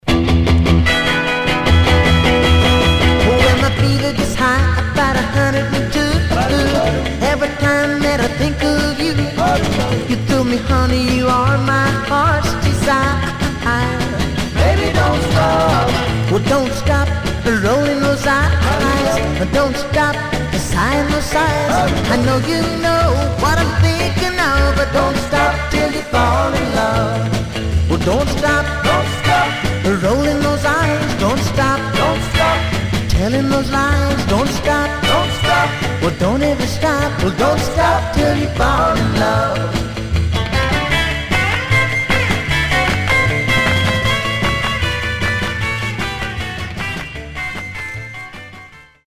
Some surface noise/wear
Mono
Rockabilly